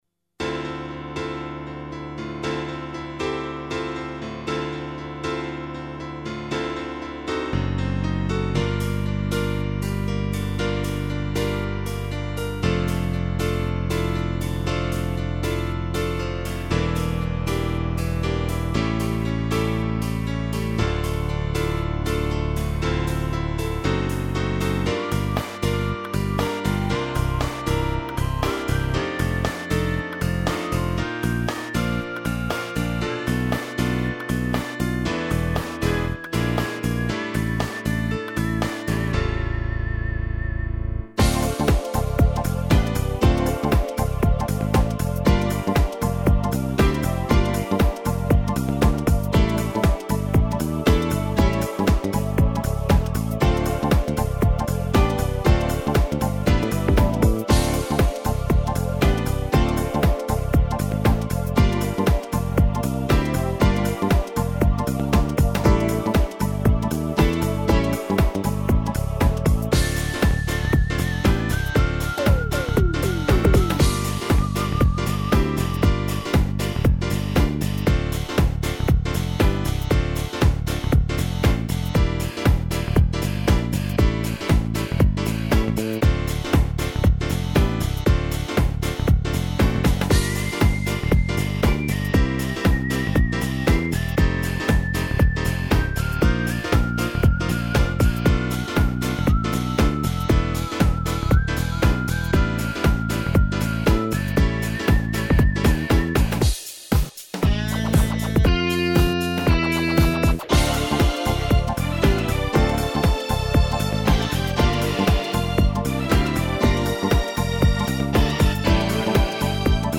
минусовка версия 225650